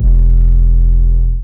808 (ItsLit).wav